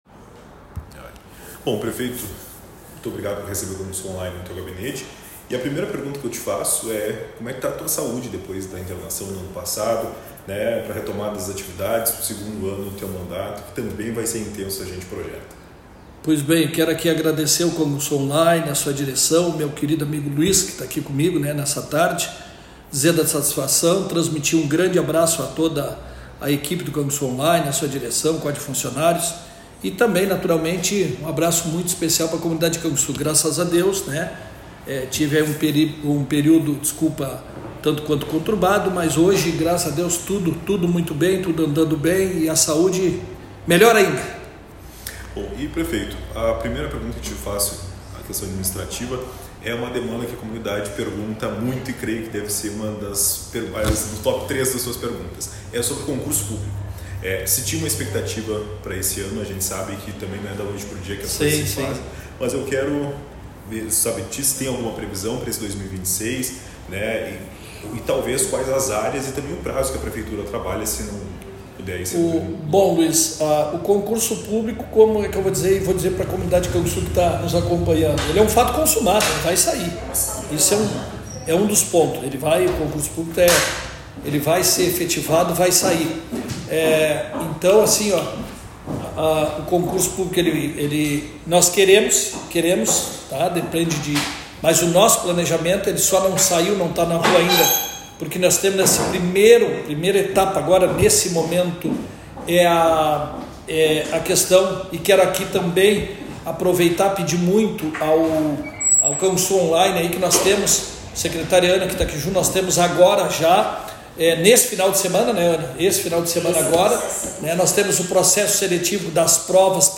Em entrevista exclusiva ao Canguçu Online, o prefeito fala sobre o prazo para o concurso público, as negociações salariais com os servidores, investimentos com recursos de empréstimo, os próximos passos da administração e as relações institucionais em ano eleitoral
Entrevista-Arion.m4a